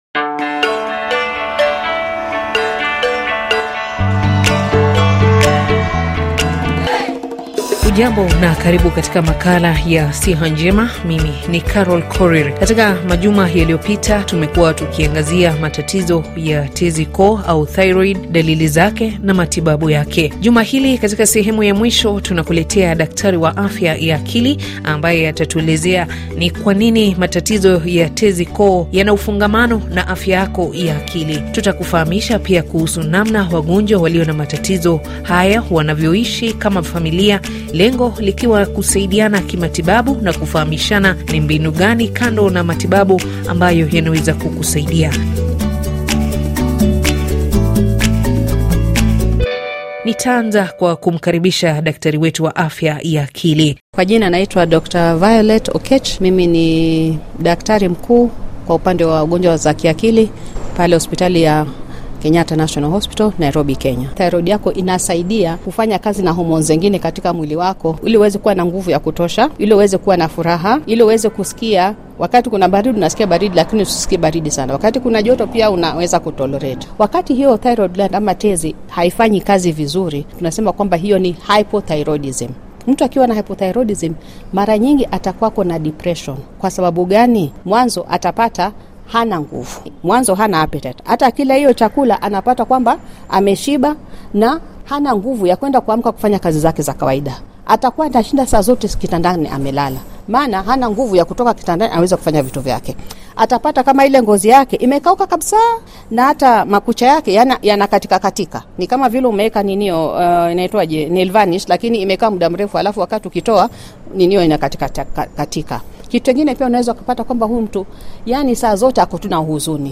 Makala inayojadili kwa kina masuala ya afya, tiba na kutambua magonjwa mbalimbali bila ya kusahau namna ya kukabiliana nayo. Watalaam walibobea kwenye sekta ya afya watakujuvya na kukuelimisha juu ya umuhimu wa kuwa na afya bora na si bora afya. Pia utawasikiliza kwa maneno yao watu ambao wameathirika na magonjwa mbalimbali na walikumbwa na nini hadi kufika hapo walipo.